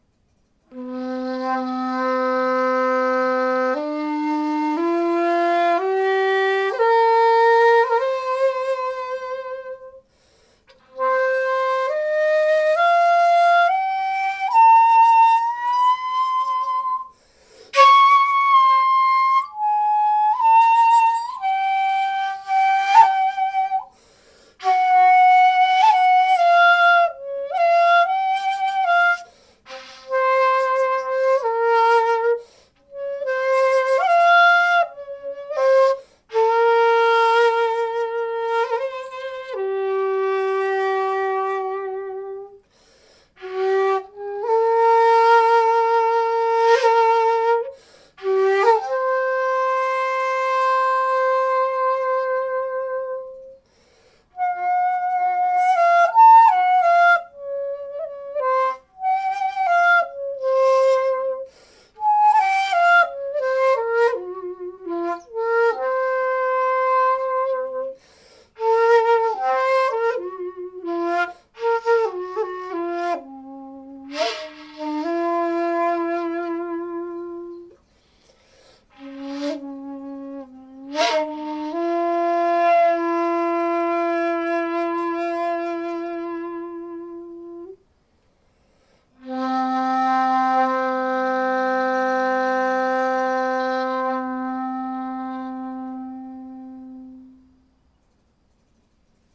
Ji-ari Shakuhachi 2.0 in C SEIHO | Atelier Chikudo
Restored Ji-ari Shakuhachi